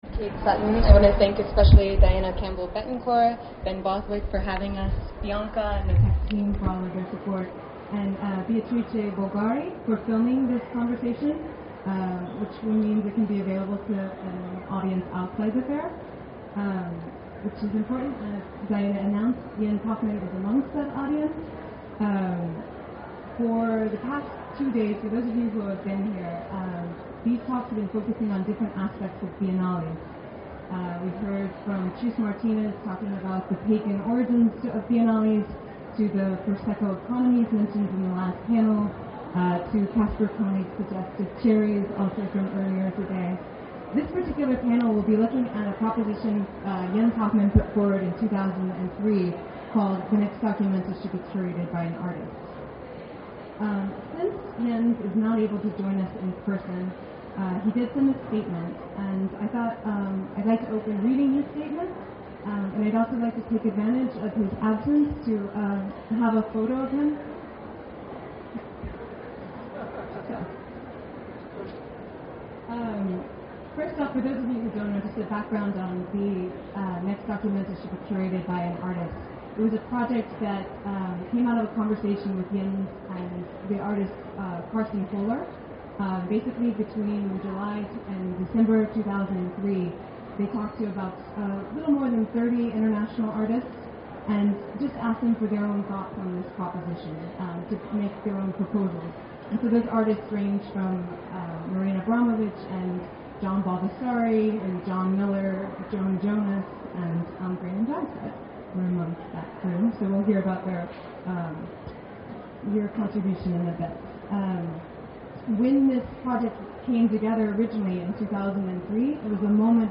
Domenica 2 aprile, presso lo spazio dedicato alla sezione miartalks, si è tenuto un incontro focalizzato sulla figura dell’artista come curatore. Invitare artisti a curare biennali e triennali è diventata ormai una pratica comune.